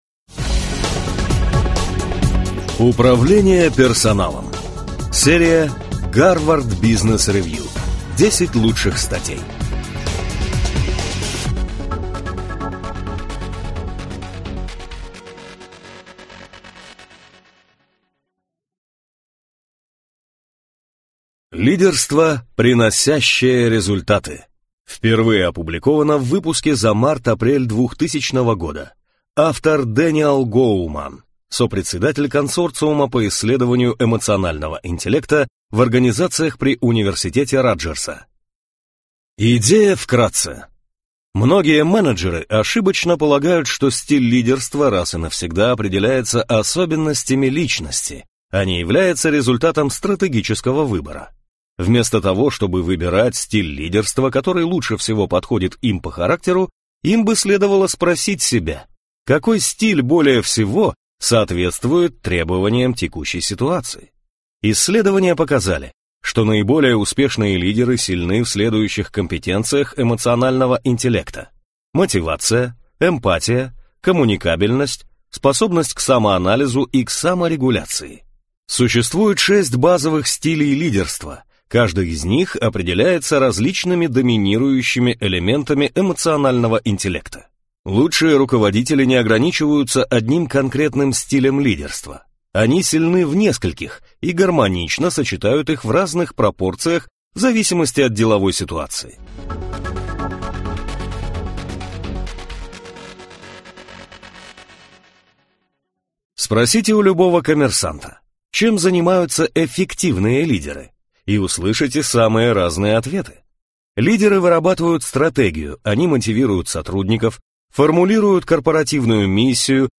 Аудиокнига Управление персоналом | Библиотека аудиокниг